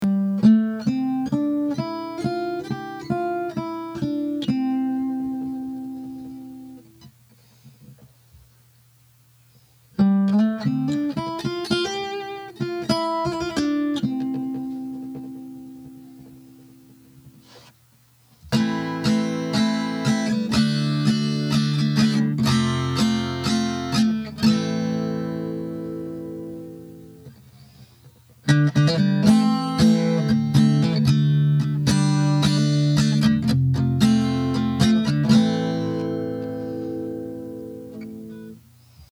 The first part is a scale in C-major played straight, then the same notes are played again, but with feeling. The second part is a chord progression in Am, again played straight, and then adding some emotion.
Same notes and chords in both examples, but with the emotion added, convey a completely different meaning.